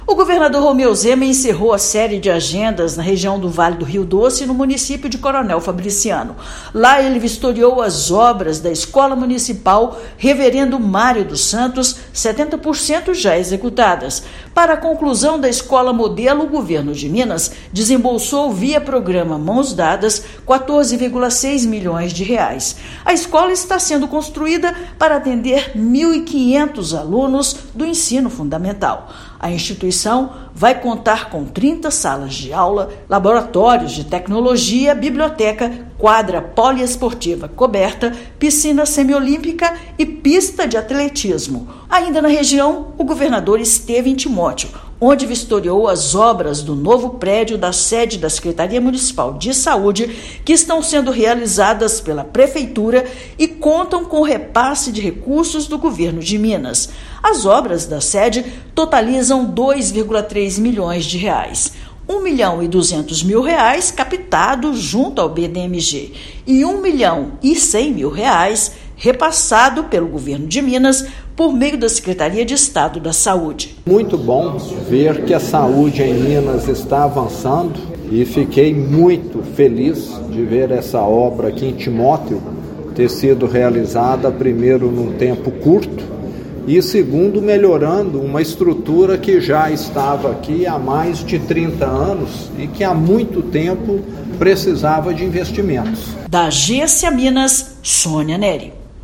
Escola modelo que recebeu quase R$ 15 milhões de recursos do Mãos Dadas vai atender mais de 1,5 mil alunos; Estado também investe em melhorias na mobilidade e atendimento médico à população. Ouça matéria de rádio.